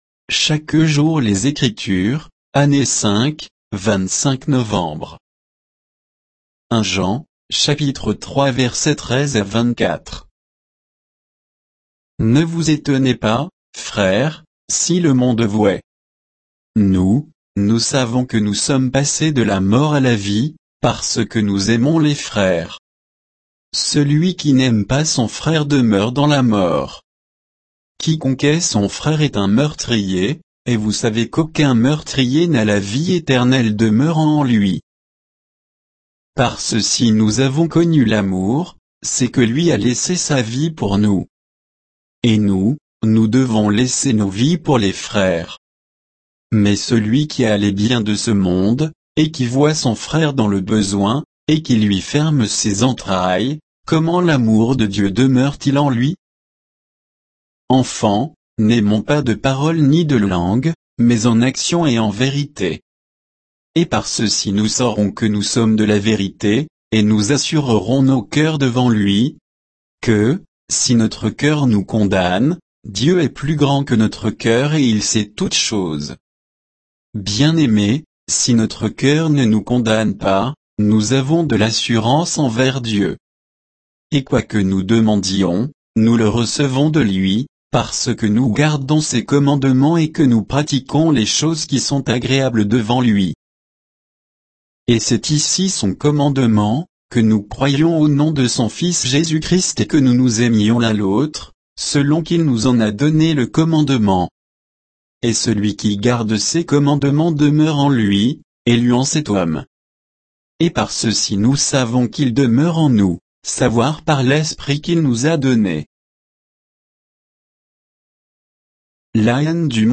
Méditation quoditienne de Chaque jour les Écritures sur 1 Jean 3, 13 à 24